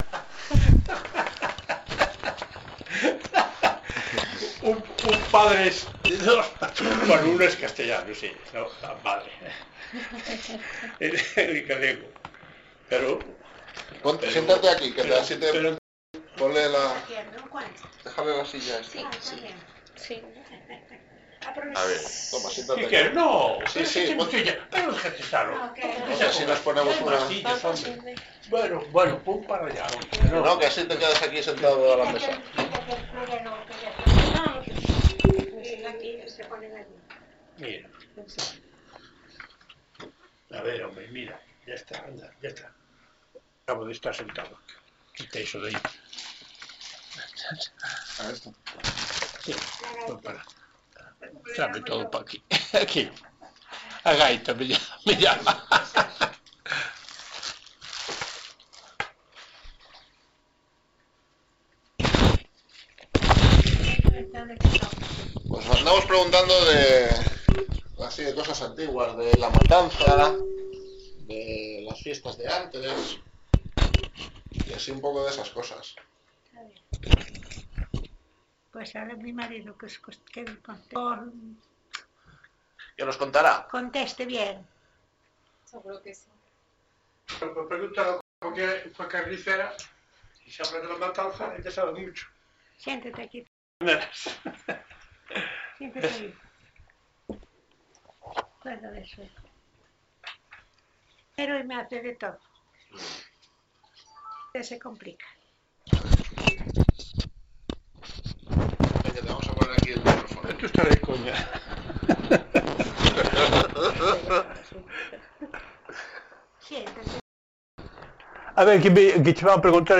Locality Guitiriz
I1: varón - 85 años, I2: mujer - 85 años aprox.